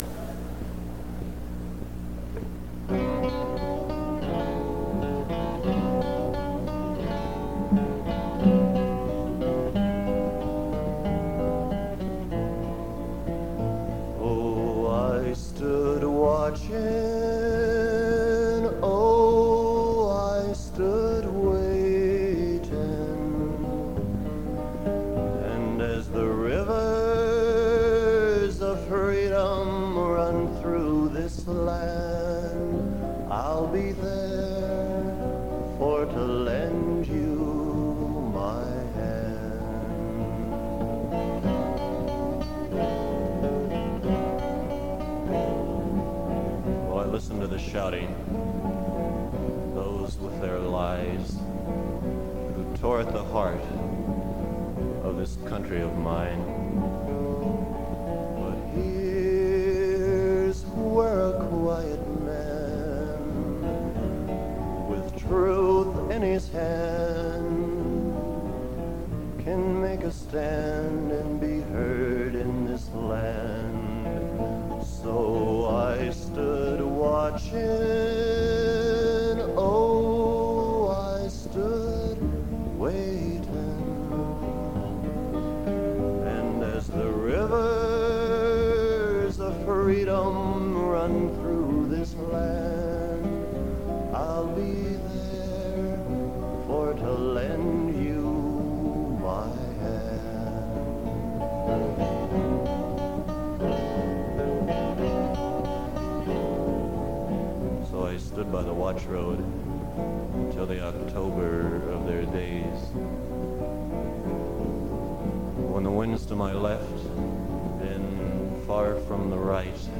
This is a bootleg of a concert from sometime in 1963.